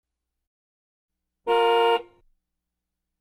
Звуки клаксона
Автомобильный звук сигнала гудка